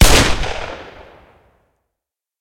revolverShootAlt.ogg